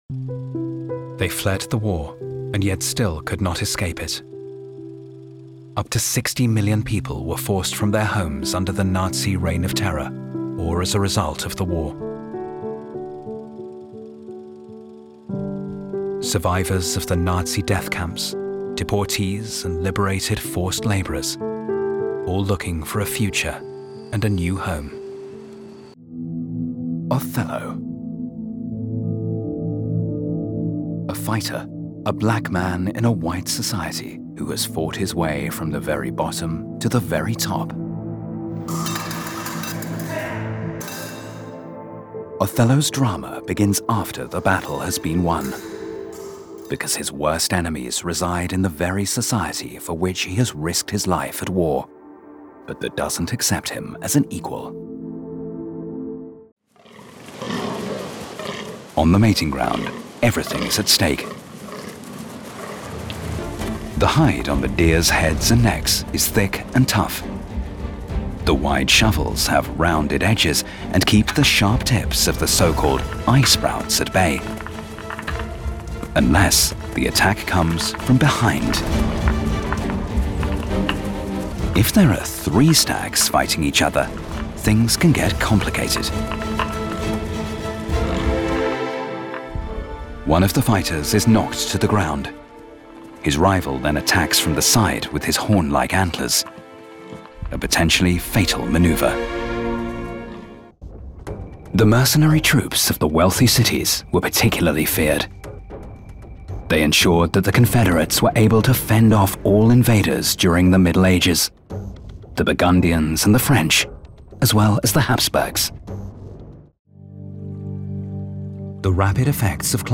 Documentary reel